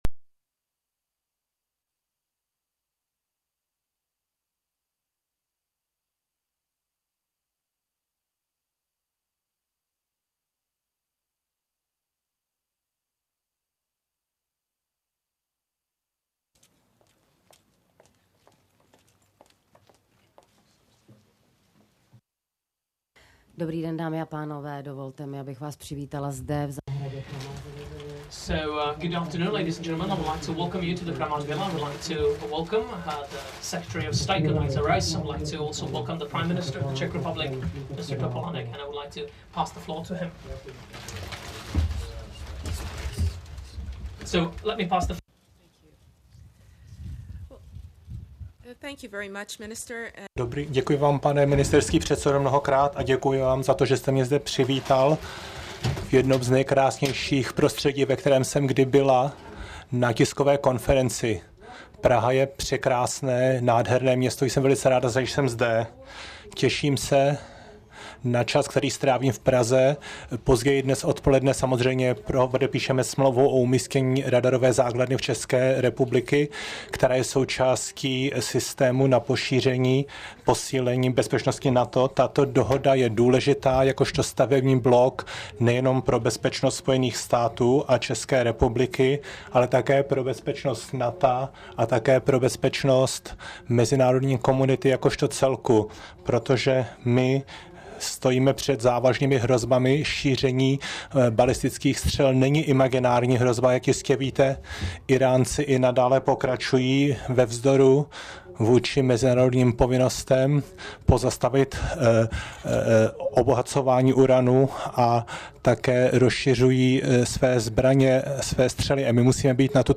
Zvukový záznam tiskové konference je k dispozici v níže připojené příloze.
Tisková konference premiéra M. Topolánka a ministryně zahraničních věcí USA C. Riceové u příleľitosti setkání v den podpisu dohody o umístění prvku protiraketové obrany na území ČR